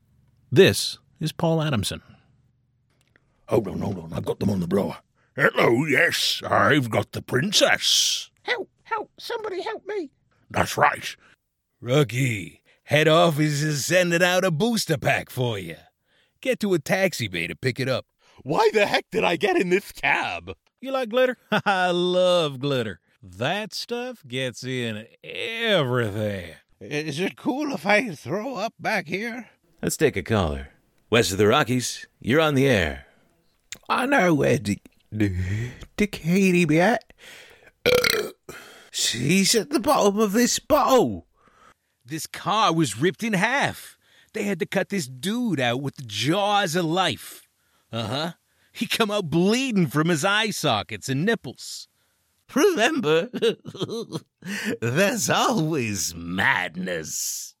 Canadian voice actor with heart